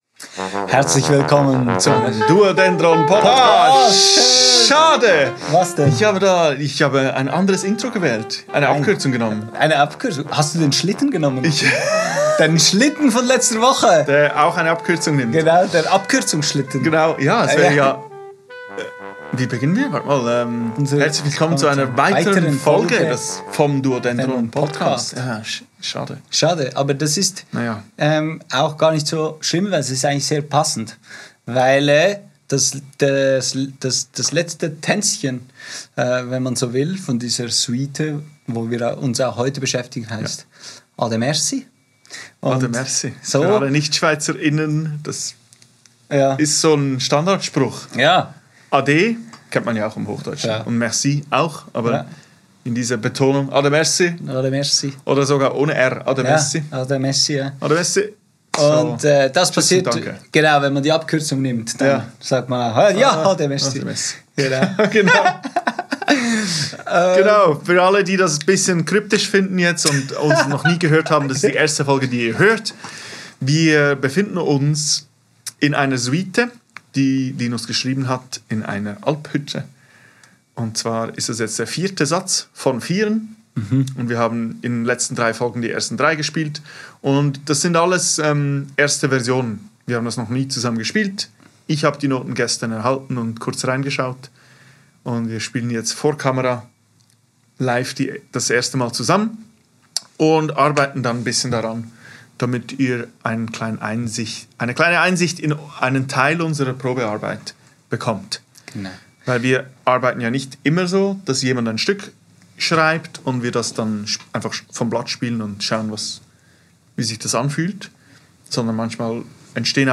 Ihr dürft uns beim Erarbeiten dieses Stückes begleiten und einen intimen Blick hinter die Kulissen werfen. Herzlich Willkommen in der duodendron Probe!
Ein leichtfüssig, aber etwas melancholisches Tänzchen im Dreivierteltakt.